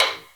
taiko-soft-hitclap.ogg